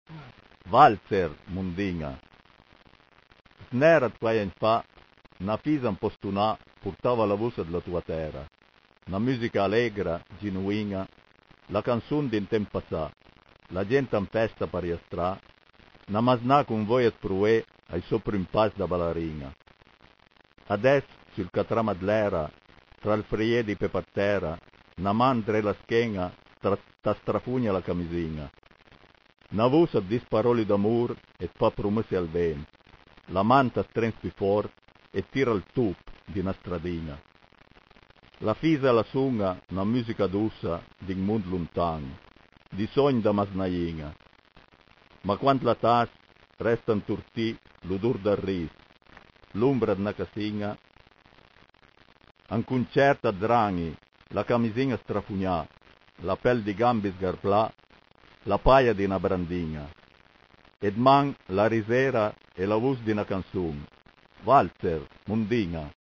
cliché chi par sénti la puizìa recità da l'autùr